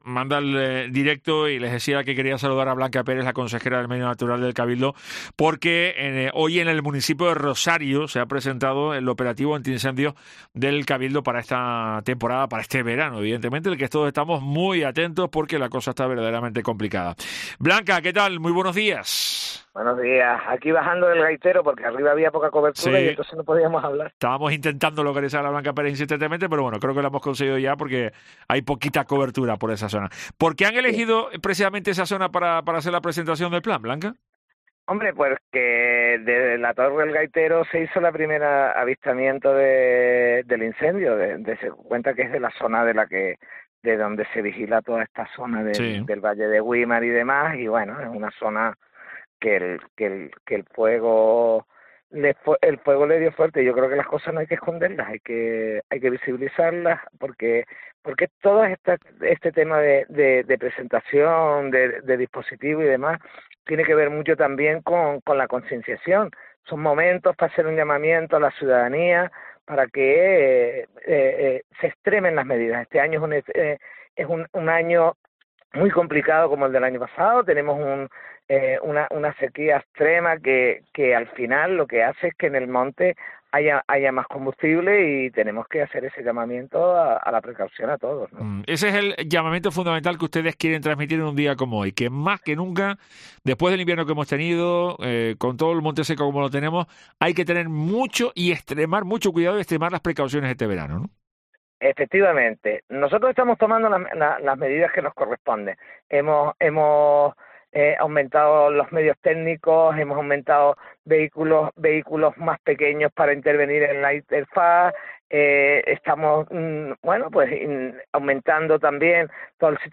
La consejera de Medio Natural, Sostenibilidad y Seguridad y Emergencias, Blanca Pérez, afirma que la isla no puede permitirse otro incendio